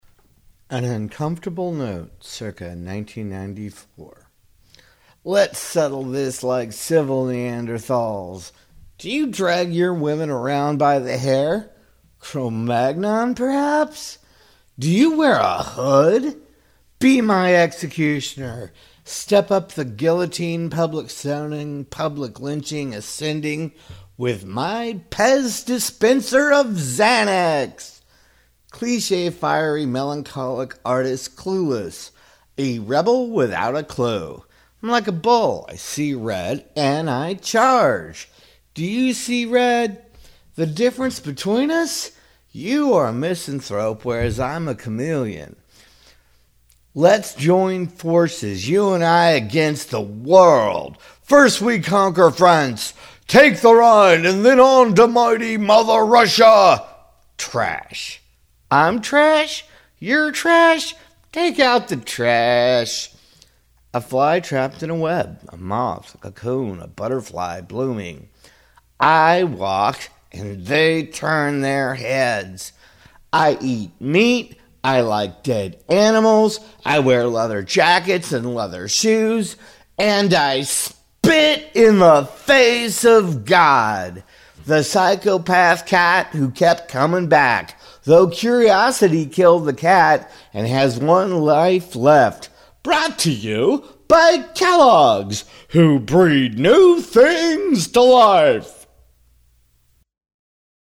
Categories: Manic Beatnik Riffing